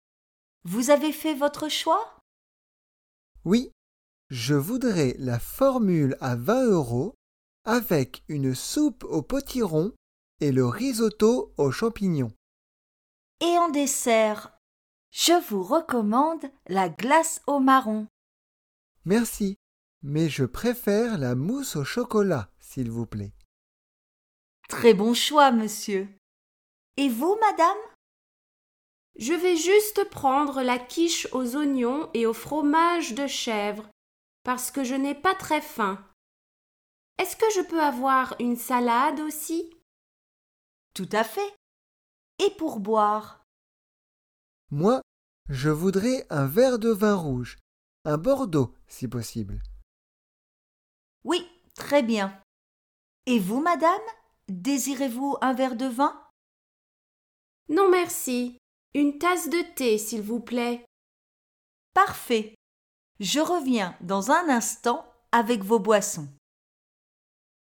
Reader-diner-d-automne-au-restaurant-2-2.mp3